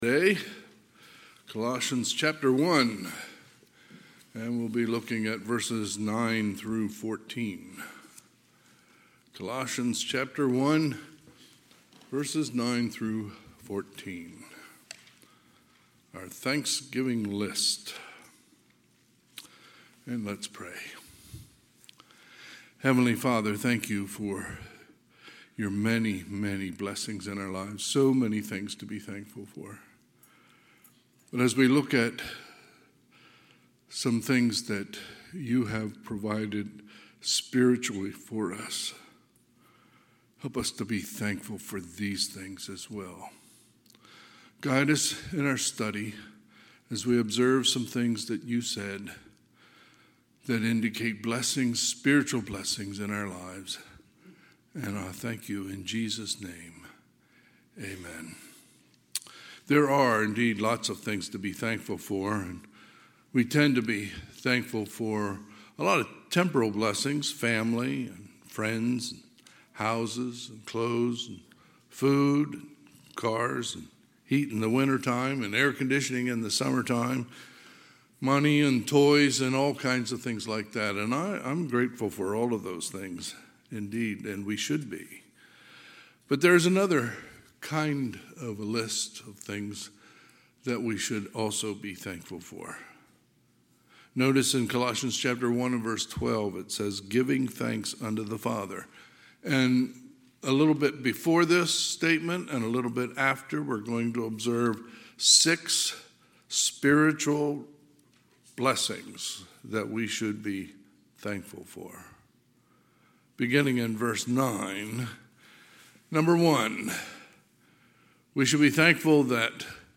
Sunday, November 26, 2023 – Sunday AM